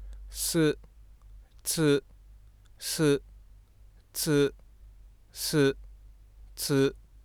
※サンプルファイルの初頭部分はノイズが目立つため，ここでは真ん中の二つのトークンのスペクトログラムを表示させています。
摩擦音は長くて立ち上がりが緩やかなのに対し，破擦音は短くて立ち上がりが急です。